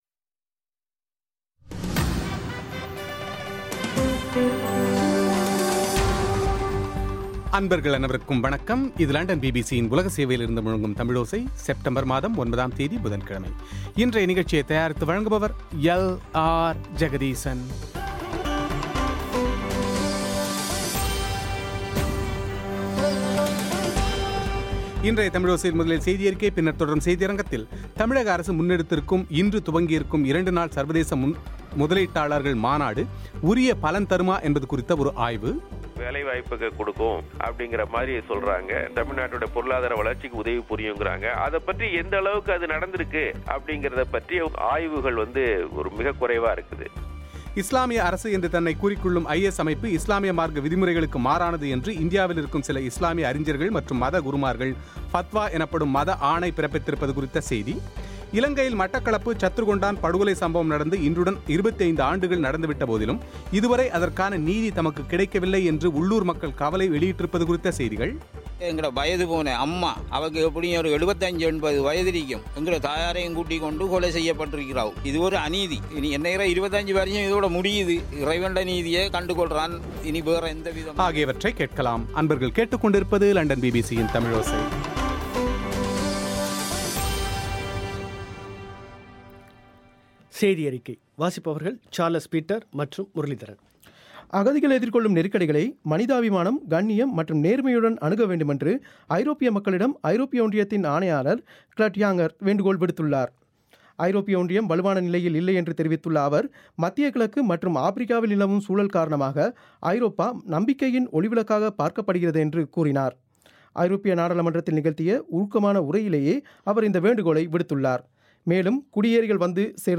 ஆய்வுச் செவ்வி